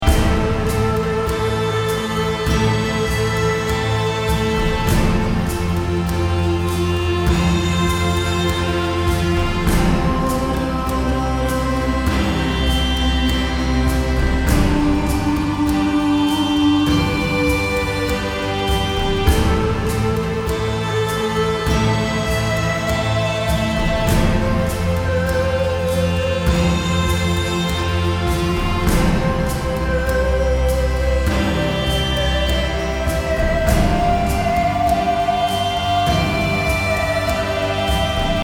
BPM 100